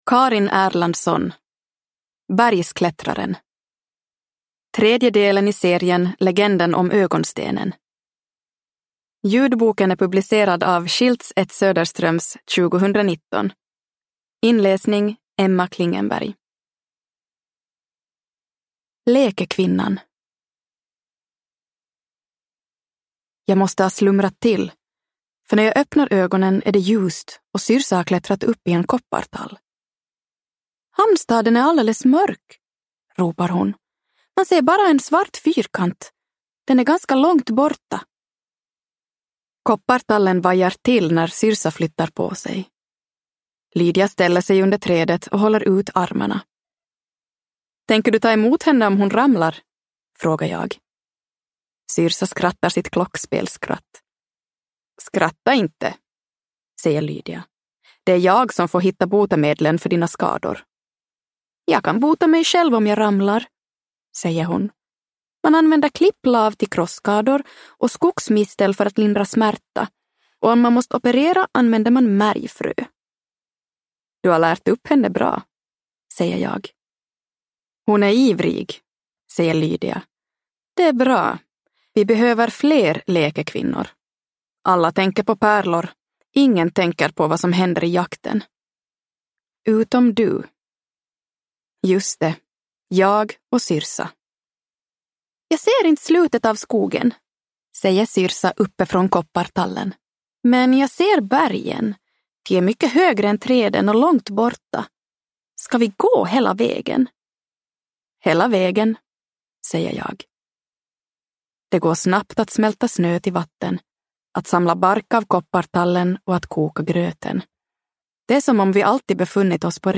Bergsklättraren – Ljudbok